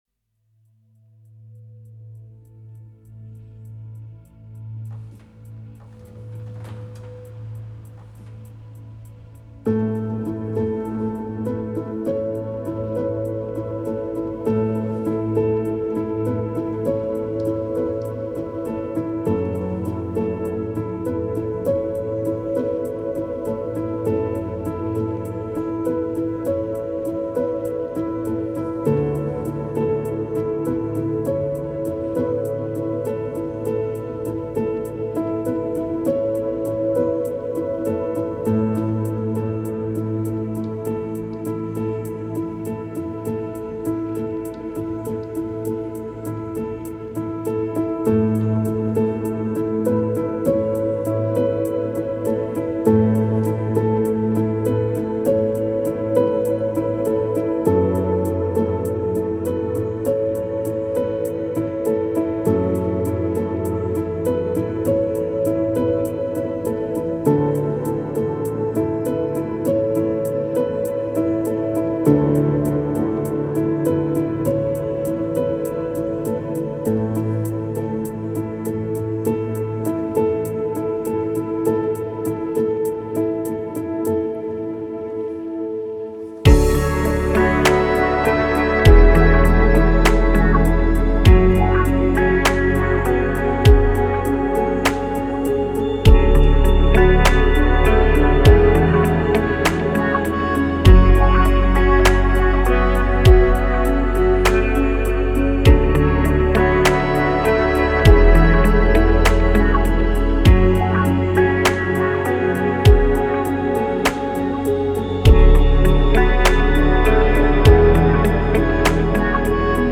Experience the uplifting melodies and positive vibes
' an inspirational acoustic track.